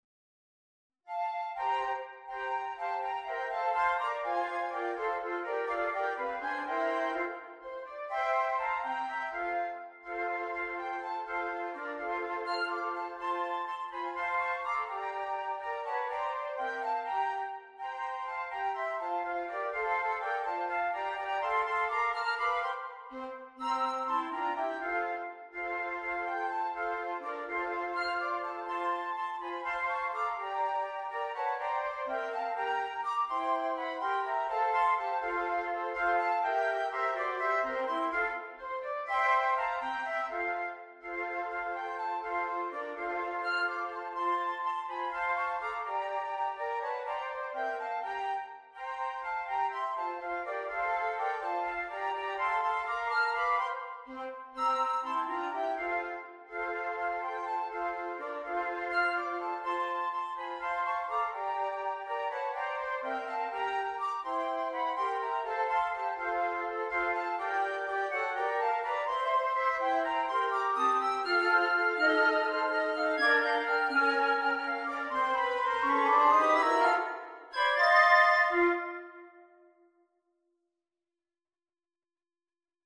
in quadruple time
Rock and Pop